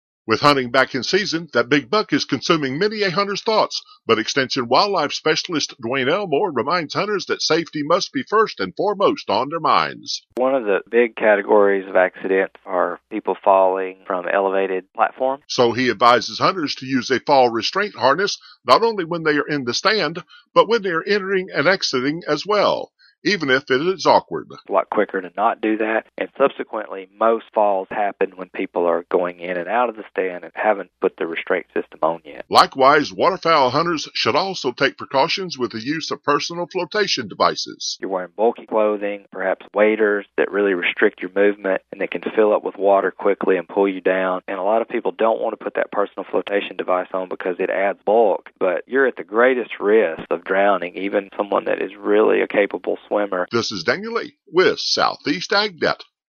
An Extension wildlife specialist provides advice that can keep hunters of all kinds safe during hunting season.